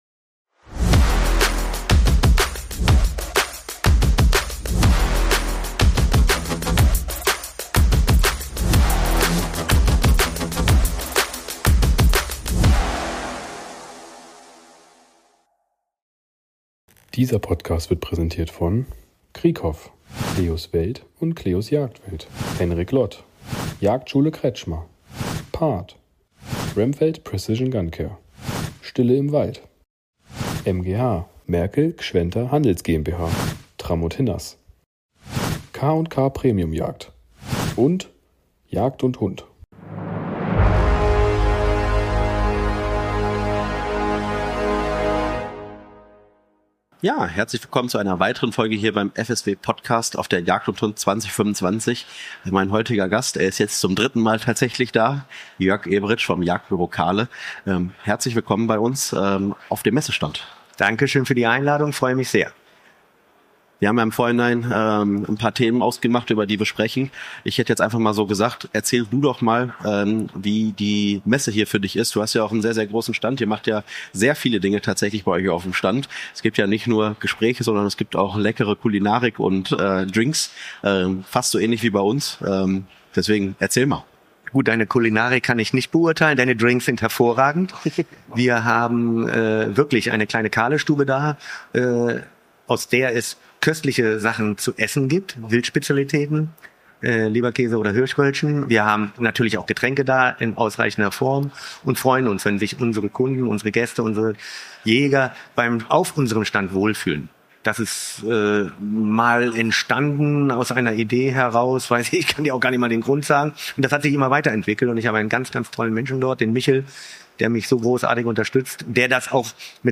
Wir sind live auf Europas größter Jagdmesse unterwegs und sprechen mit spannenden Gästen aus der Jagdszene. Ob Experten, Hersteller oder passionierte Jäger – in unseren Interviews gibt’s exklusive Einblicke, spannende Neuheiten und echte Insider-Talks rund um die Jagd. Welche Trends gibt’s 2025?